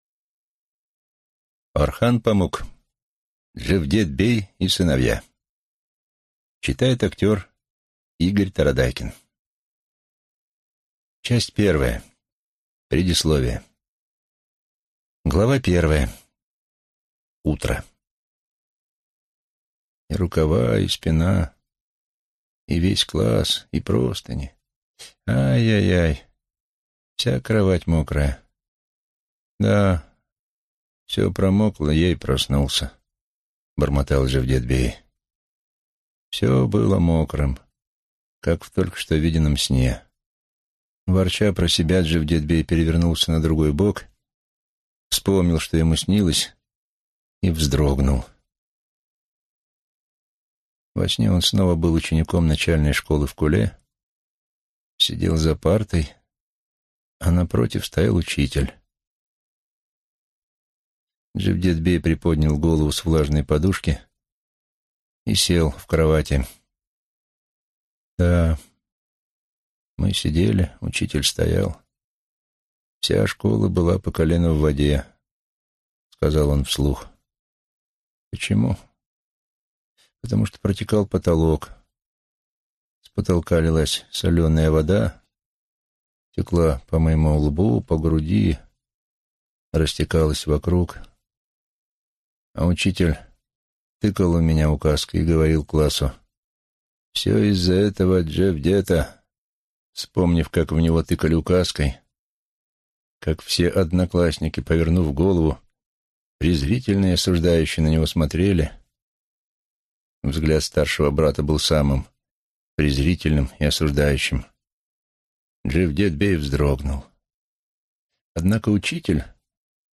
Аудиокнига Джевдет-бей и сыновья | Библиотека аудиокниг